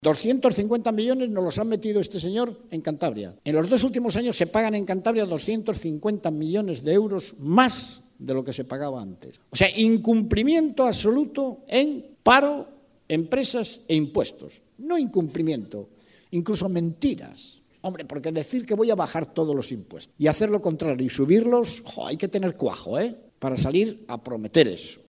Miguel Ángel Revilla durante su intervención en Laredo